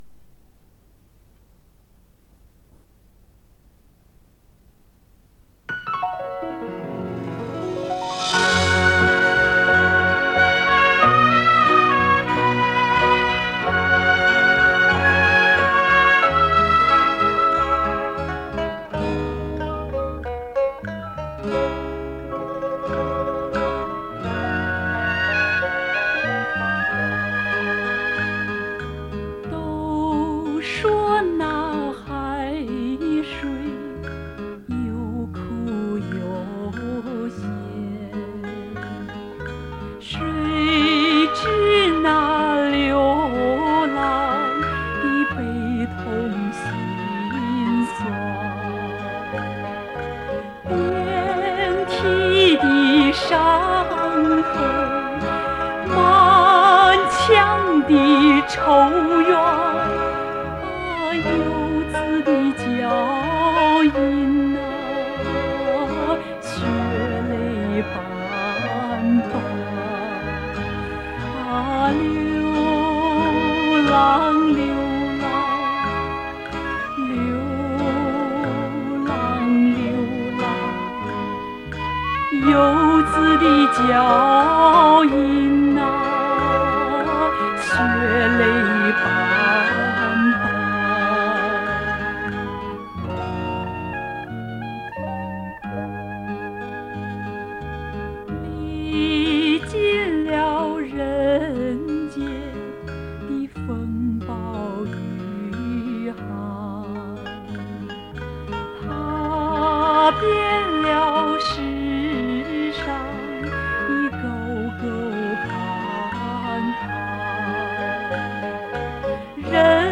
主题歌